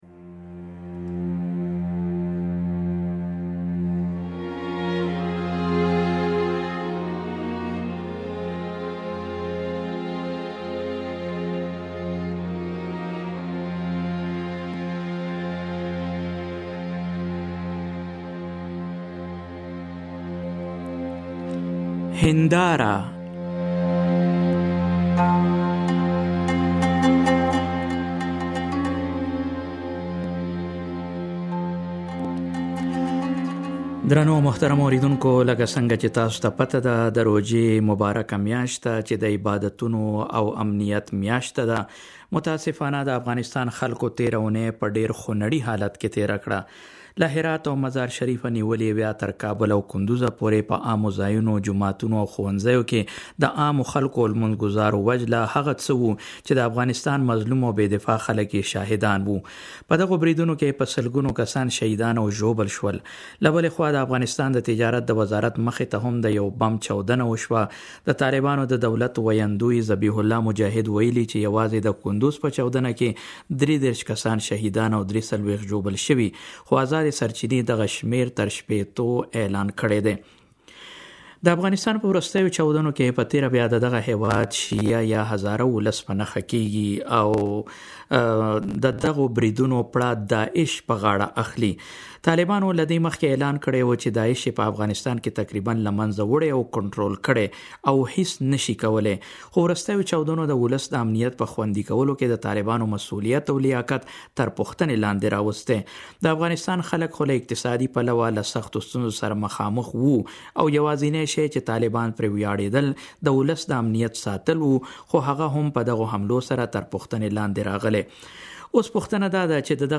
مرکښې